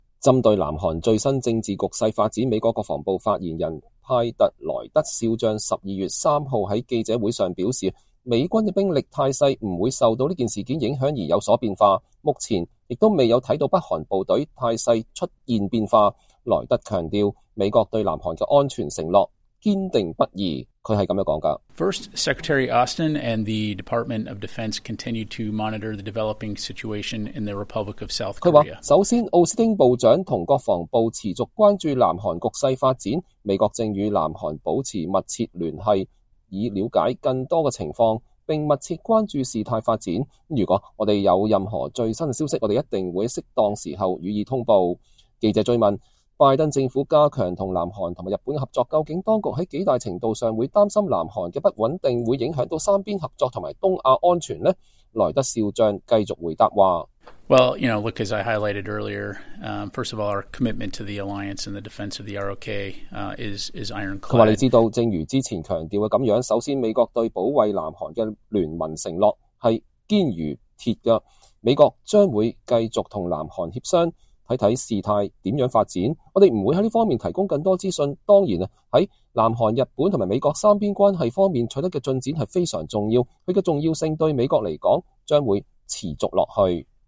針對南韓最新政治局勢發展，美國國防部發言人派特.萊德少將(Pat Ryder)12月3日在記者會上表示，美軍的兵力態勢不受此事件影響而有所變化，目前也沒有看到北韓部隊態勢出現變化。萊德強調，美國對南韓的安全承諾堅定不移。